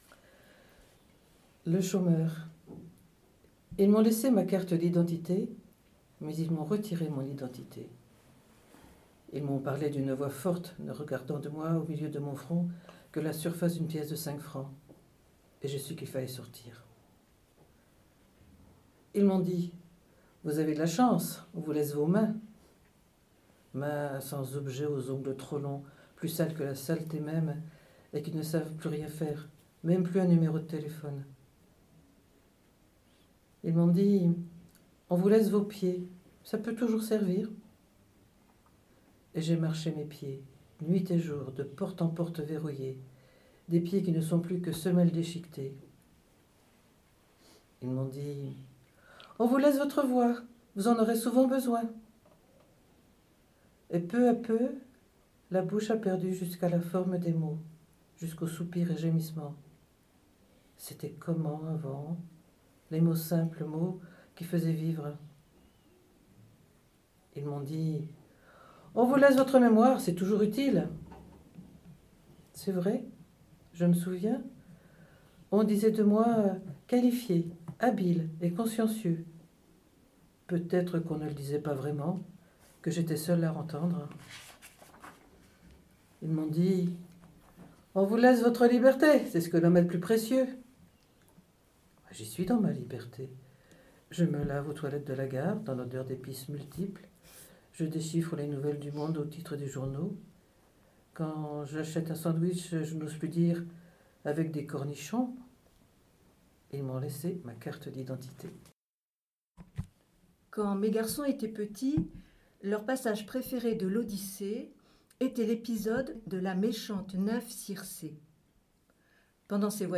Les Haut Parleurs de l'association "Lire à Saint-Lô" prêtent leurs voix sur MDR dans l’ émission "Lire et délire" ! Dans cette émission, nous écoutons des extraits des livres suivants : « Mme Pylinska et le secret de chopin » d’Eric Schmitt « Sa majesté ver-de-terre» de Piret Raud « Je haïs les femmes » de Dorothy Parker «Le petit prince » de Antoine de Saint Exupéry, "Chagrin d'aimer" de Genevieve Brisac, " Les philos fables pour la terre " de Michel Piquemal, "Courts et légendes de l'oiseau" de Michel Bournaud, " Les pas perdus " de Denise Bonal et "Une odyssée" de Daniel Mendelson.